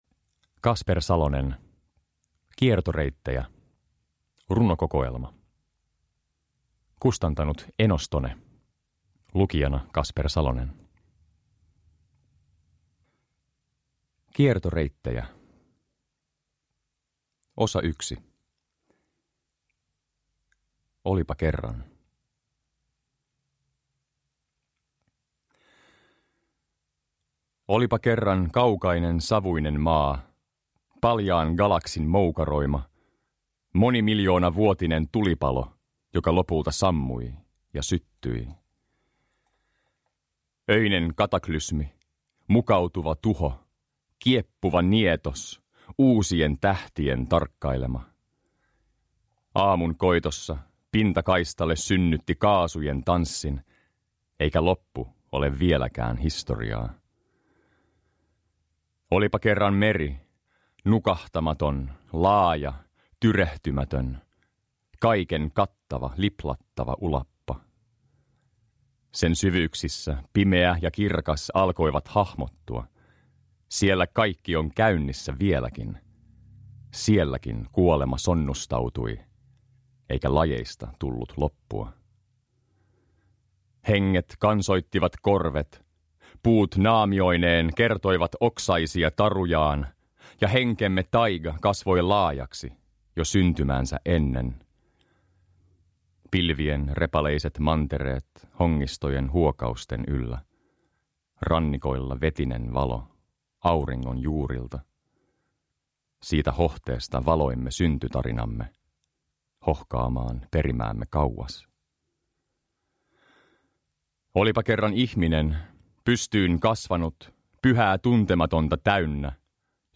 Kiertoreittejä – Ljudbok